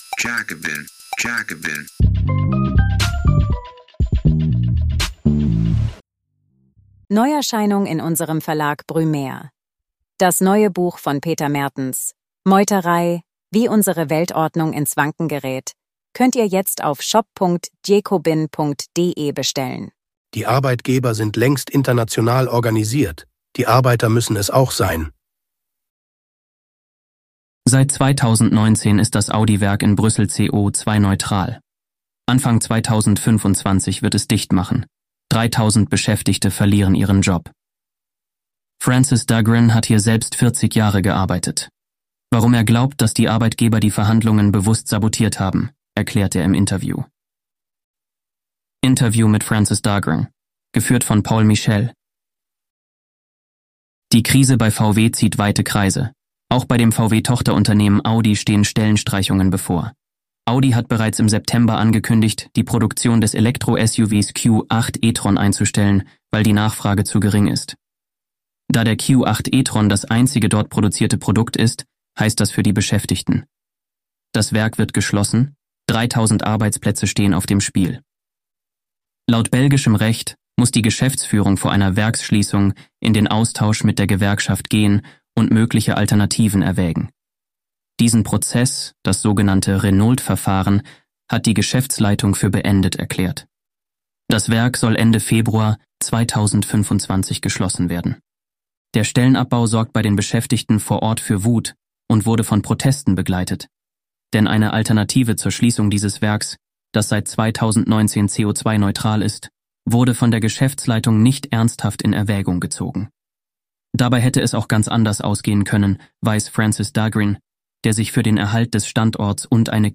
Warum er glaubt, dass die Arbeitgeber die Verhandlungen bewusst sabotiert haben, erklärt er im Interview.